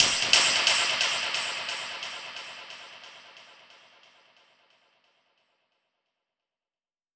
Index of /musicradar/dub-percussion-samples/134bpm
DPFX_PercHit_C_134-02.wav